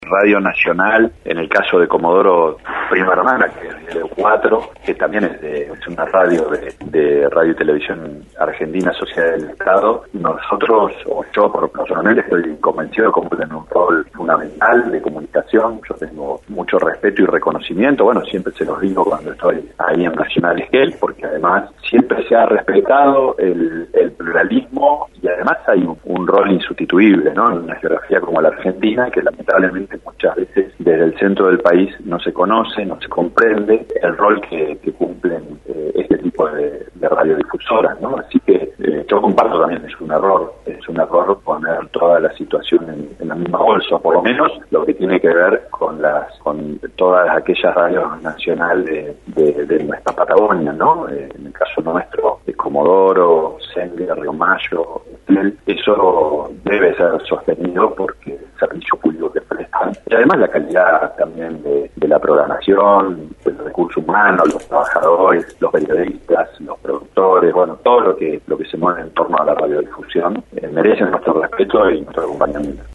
El Vice Gobernador de la provincia de Chubut, Gustavo Menna, en diálogo con Radio Nacional Esquel se manifestó de defensa de la emisora estatal, expresando “tengo mucho respeto y reconocimiento por Radio Nacional ya que cumplen un rol fundamental de comunicación”, valorando además que “se ha respetado el pluralismo y hay un rol insustituible” y destacando también la “calidad de la programación y el recurso humano”.